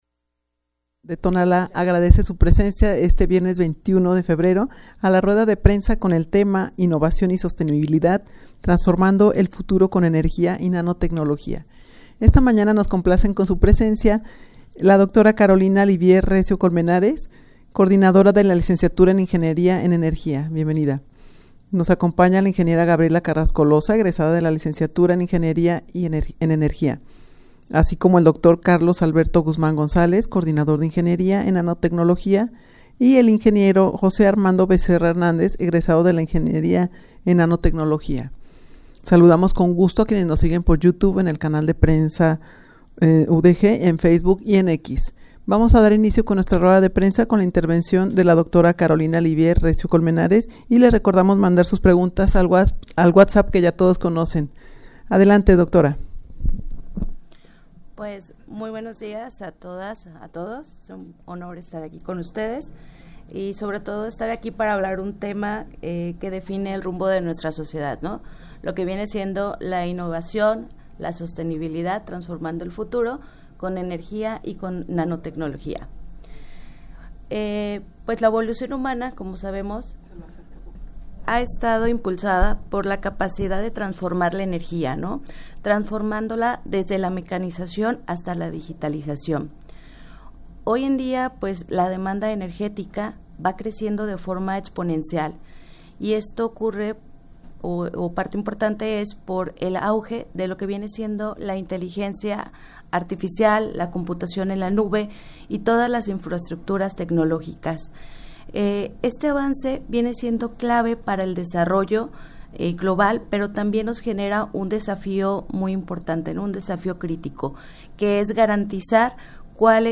Académicos y especialistas del CUTonalá hablaron de las bondades de estudiar las carreras en Energía y Nanotecnología
Audio de la Rueda de Prensa
rueda-de-prensa-con-el-tema-innovacion-y-sostenibilidad-transformando-el-futuro-con-energia-y-nanotecnologia.mp3